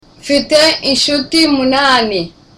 (Smiling)